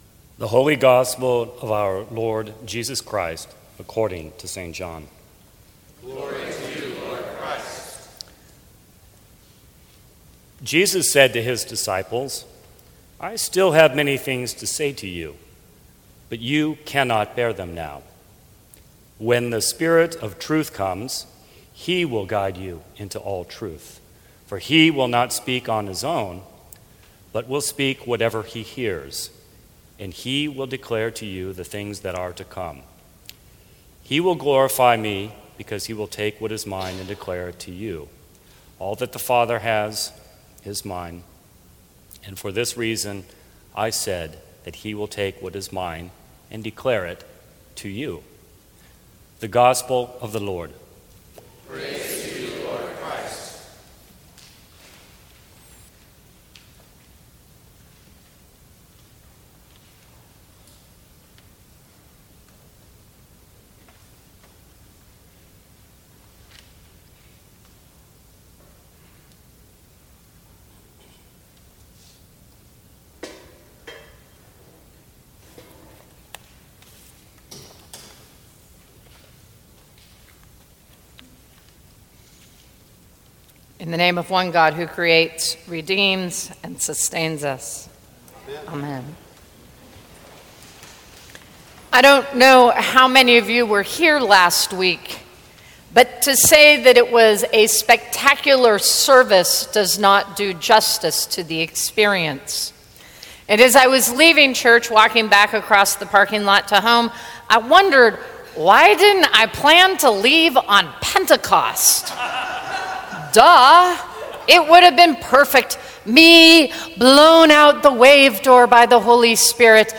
Sermons from St. Cross Episcopal Church Trinity Sunday!